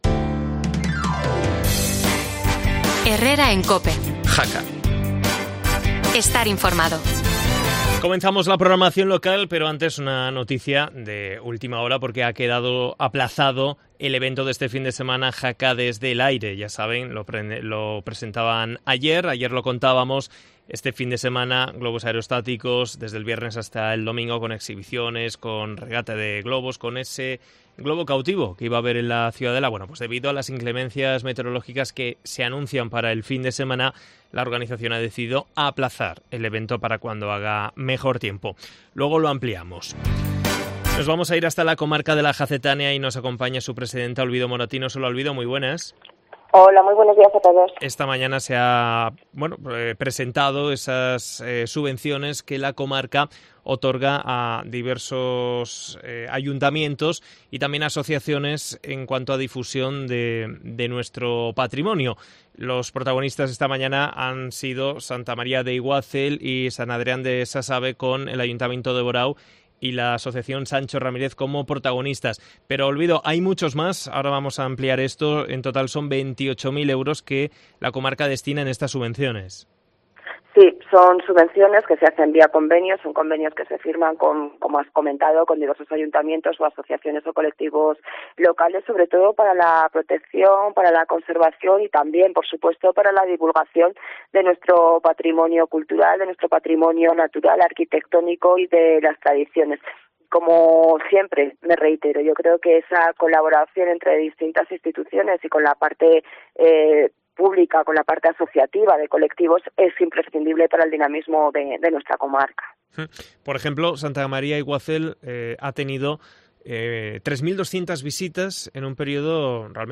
rueda de prensa